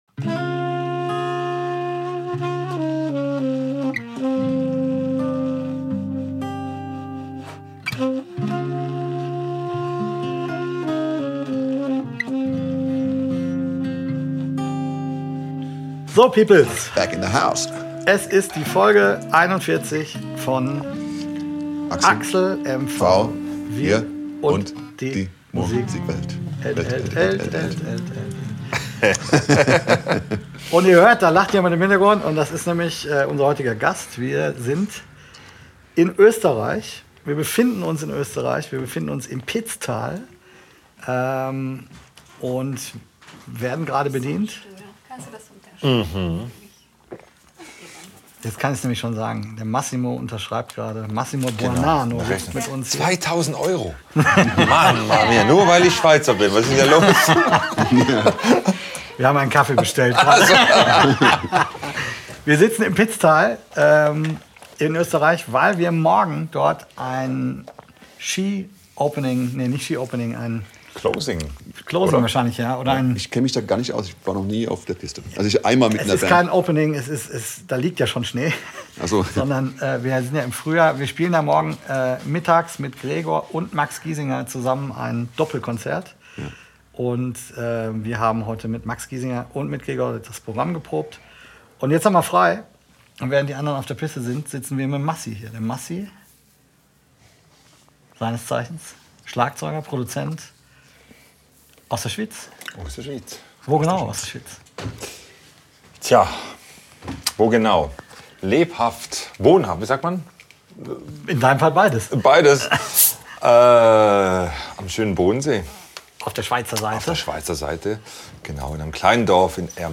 in einem alpenländischen Hotel im Pitztal